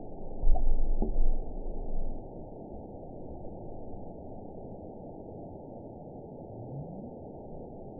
event 910694 date 01/29/22 time 21:08:46 GMT (3 years, 3 months ago) score 9.51 location TSS-AB01 detected by nrw target species NRW annotations +NRW Spectrogram: Frequency (kHz) vs. Time (s) audio not available .wav